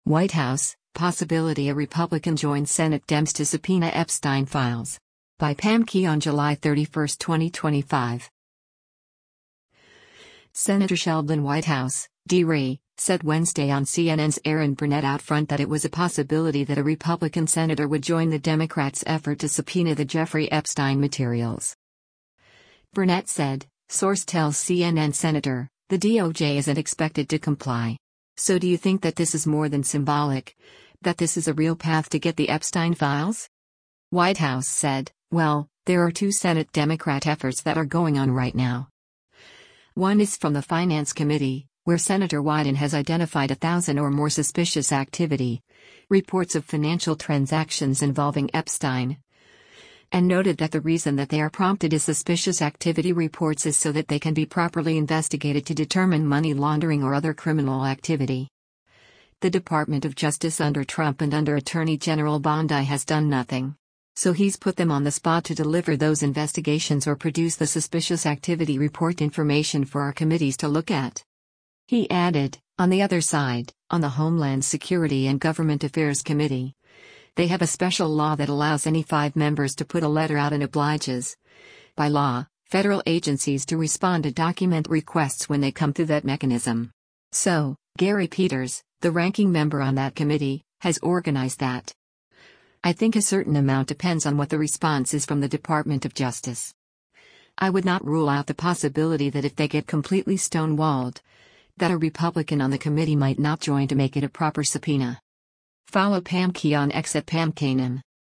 Senator Sheldon Whitehouse (D-RI) said Wednesday on CNN’s “Erin Burnett OutFront” that it was a “possibility” that a Republican Senator would join the Democrats’ effort to subpoena the Jeffrey Epstein materials.